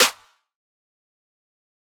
Metro Snares [Killer].wav